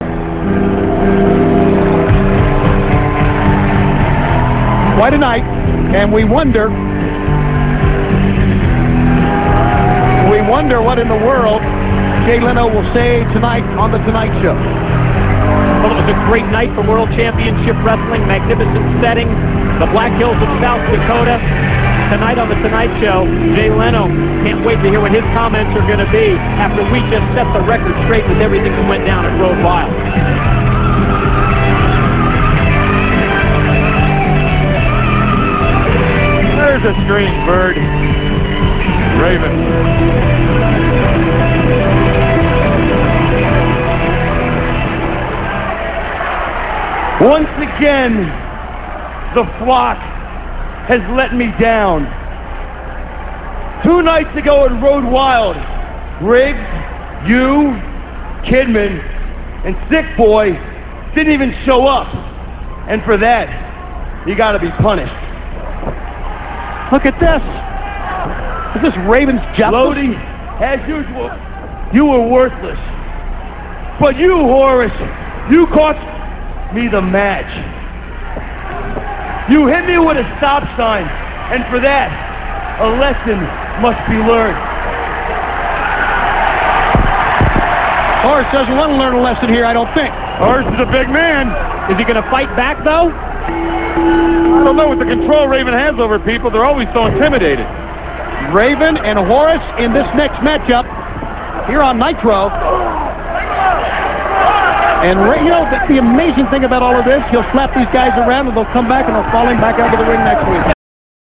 - This speech comes from Nitro - [7.10.98]. Raven insists that the Flock has let them down after his loss against Saturn and Kanyon at Road Wild - (1:51)